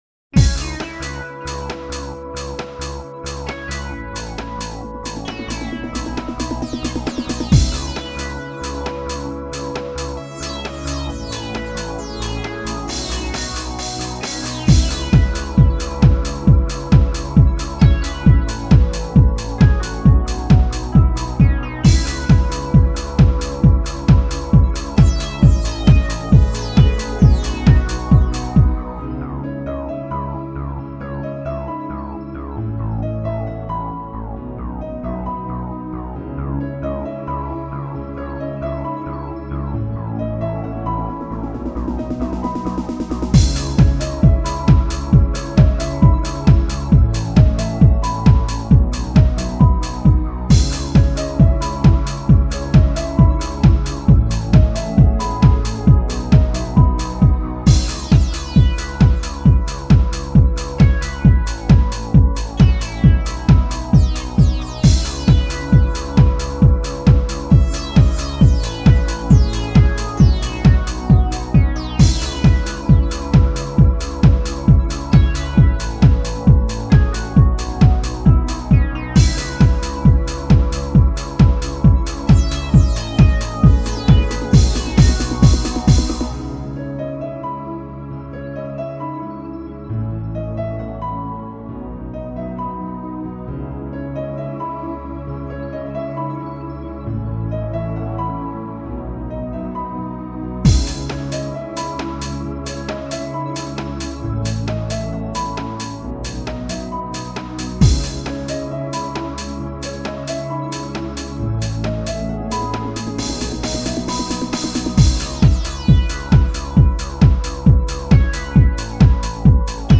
(trance mix)